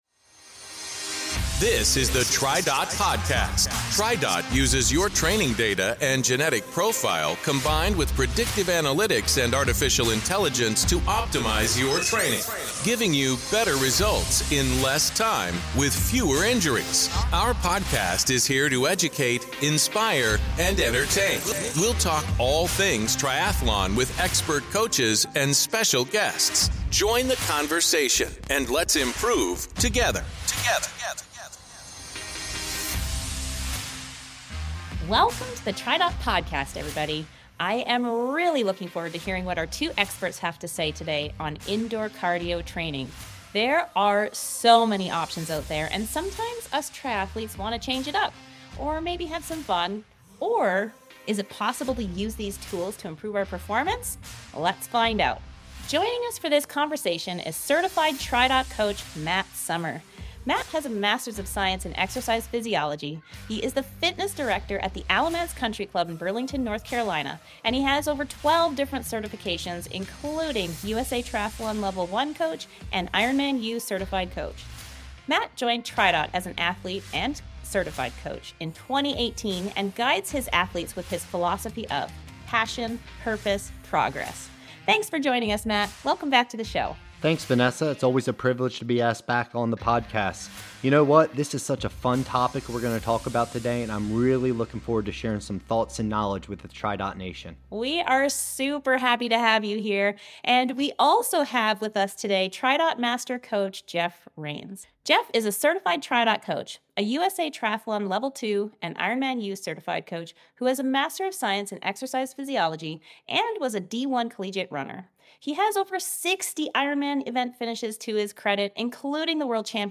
0:21.7 We'll talk all things triathlon with expert coaches and special guests.